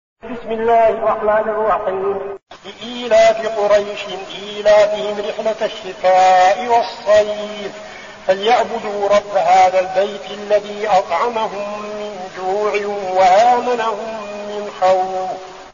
المكان: المسجد النبوي الشيخ: فضيلة الشيخ عبدالعزيز بن صالح فضيلة الشيخ عبدالعزيز بن صالح قريش